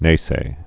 (nāsā)